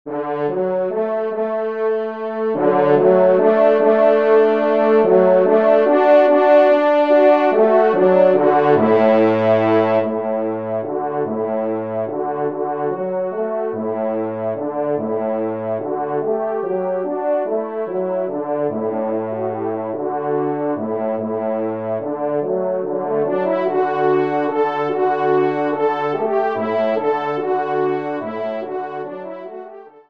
Genre :  Divertissement pour Trompes ou Cors en Ré
6e Trompe